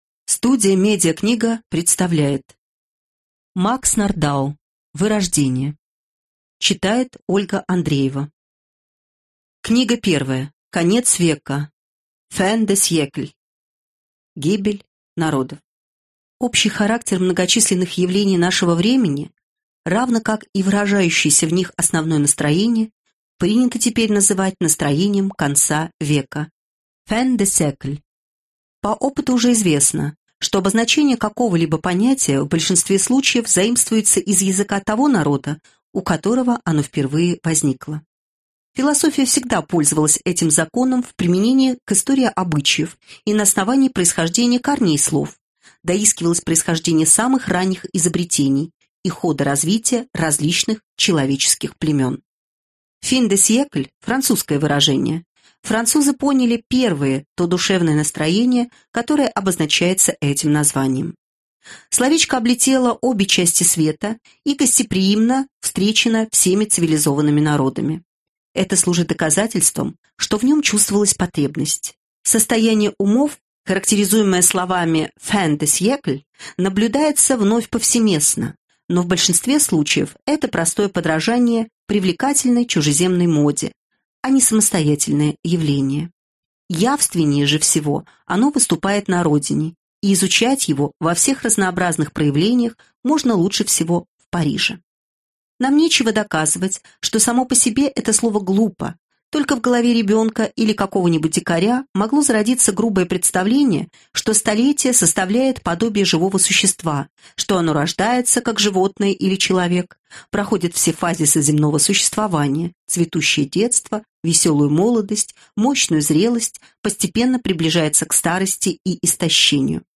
Аудиокнига Вырождение | Библиотека аудиокниг